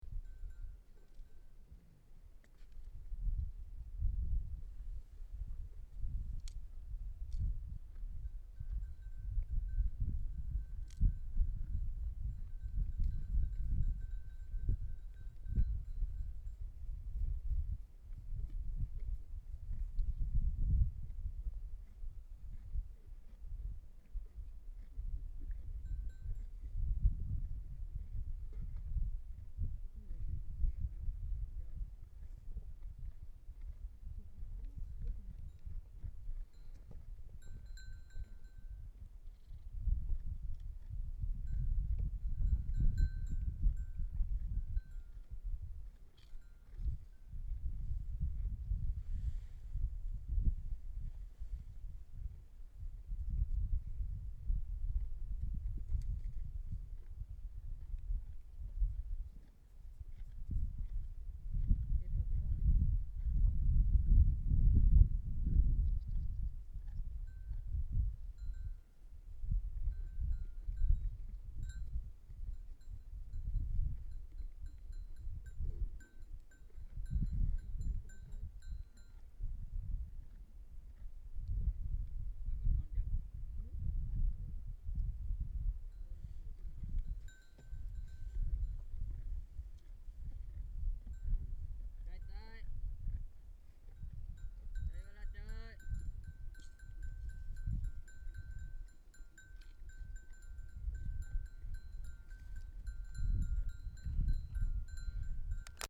desert4_silence.mp3